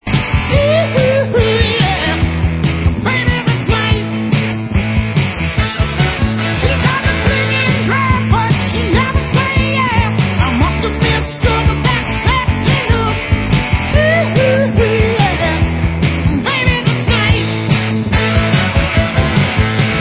• styl: Glam